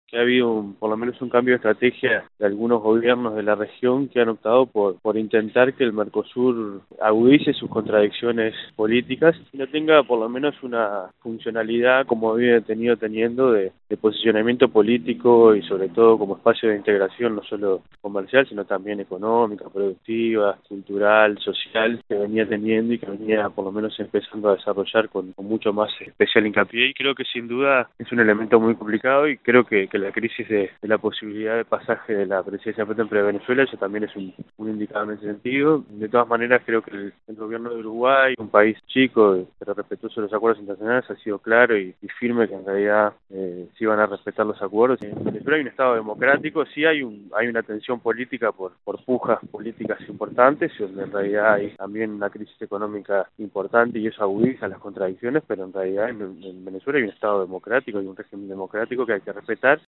Entrevista al Parlamentario uruguayo y Vicepresidente del PARLASUR, Daniel Caggiani realizada por 'Informe Nacional'